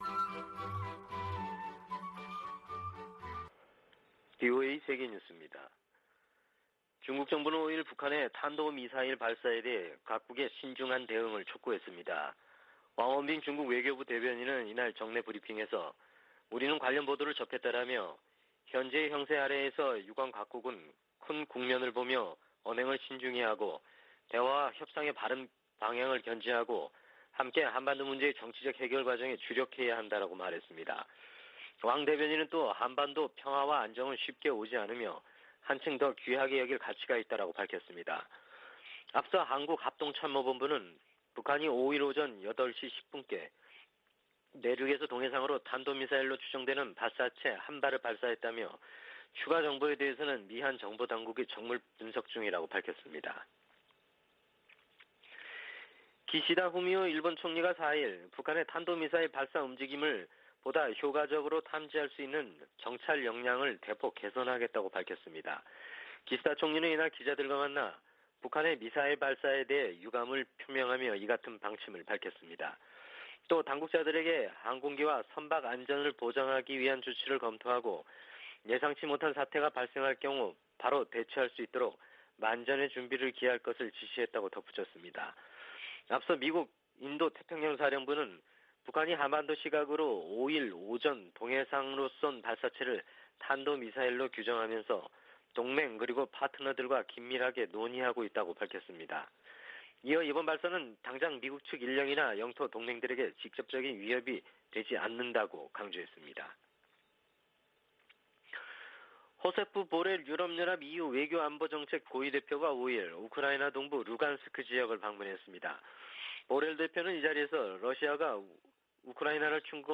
VOA 한국어 아침 뉴스 프로그램 '워싱턴 뉴스 광장' 2021년 1월 6일 방송입니다. 북한이 동해상으로 탄도미사일로 추정되는 발사체를 쐈습니다.